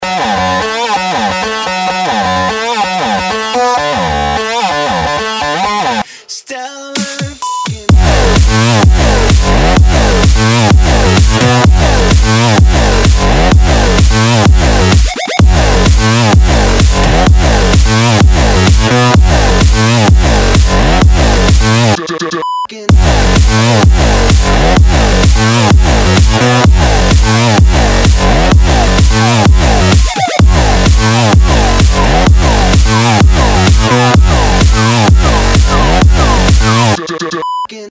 • Качество: 192, Stereo
громкие
Драйвовые
electro
Ремикс, наполняющий энергией твой телефон при звонке!